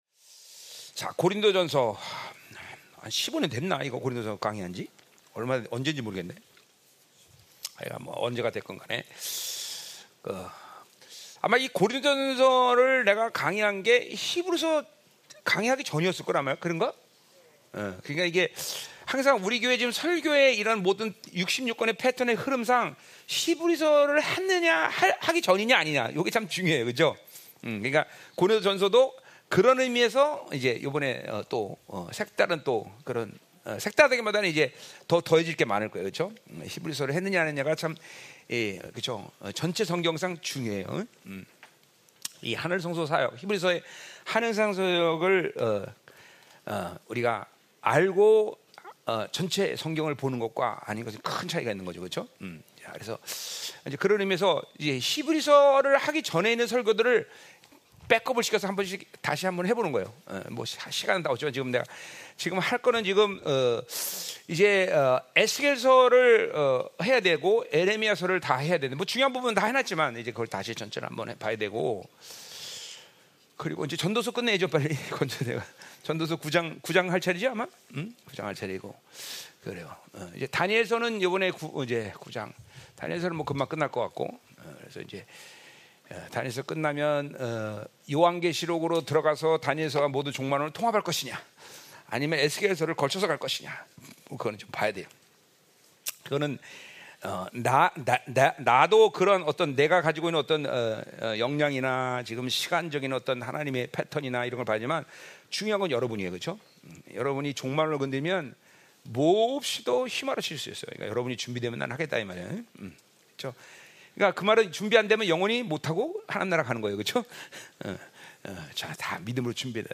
수요예배
고린도전서 강해